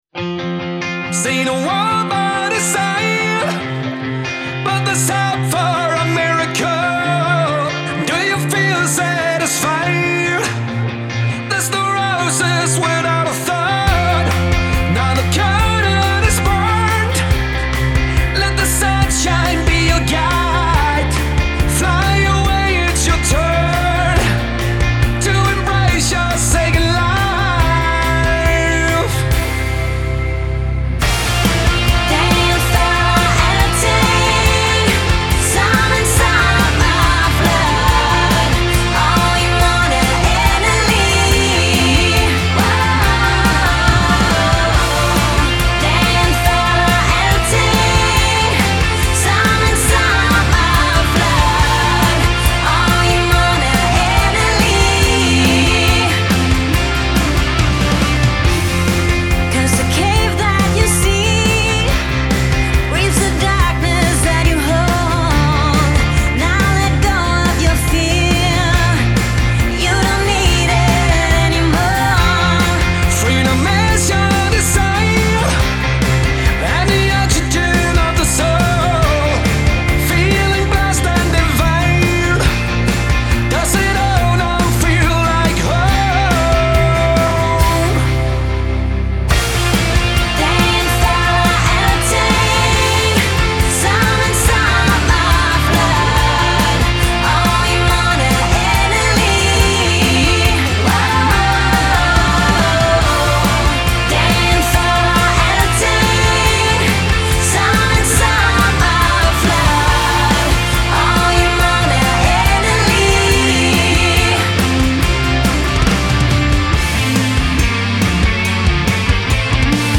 Genre : Metal